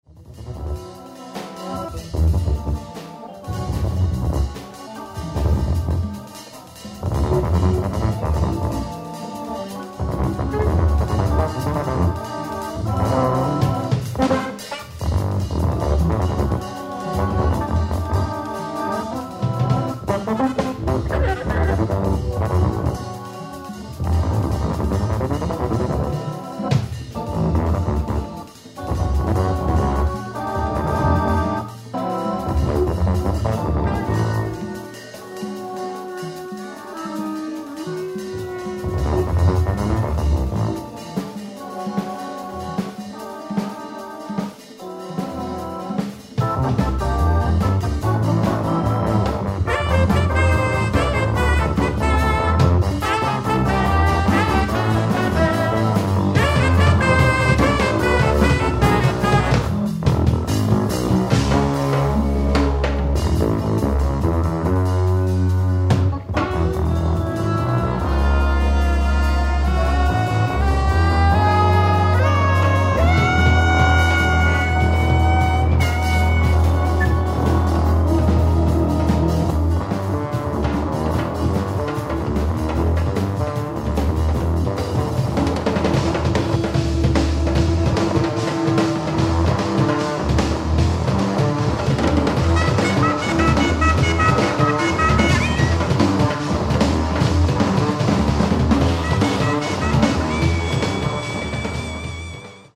ライブ・アットクニッテルフェルト、シュタイアーマルク、アーストリア 07/01/1983
※試聴用に実際より音質を落としています。